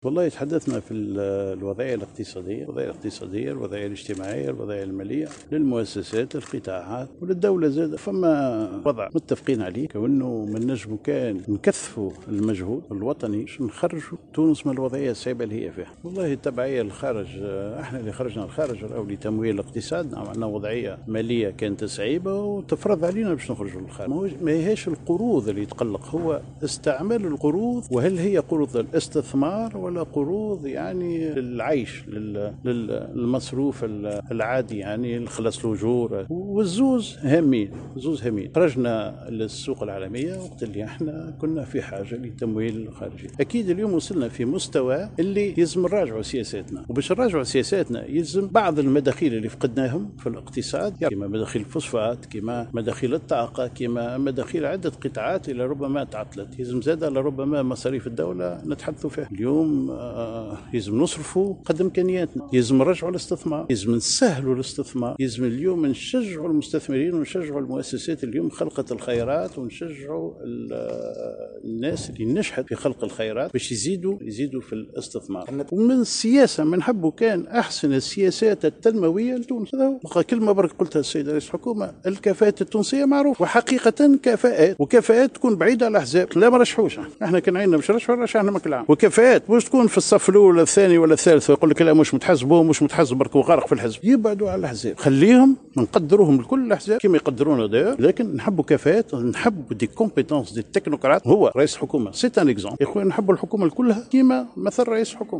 أفاد رئيس الإتحاد التونسي للصناعة والتجارة والصناعات التقليدية سمير ماجول في تصريح لـ "الجوهرة أف أم"اليوم إثر لقائه برئيس الحكومة المكلف هشام مشيشي، بأنه تم التطرق خلال اللقاء إلى الوضعية الإقتصادية والإجتماعية والمالية، داعيا إلى تكثيف المجهود الوطني لإخراج البلاد من الوضعية الصعبة.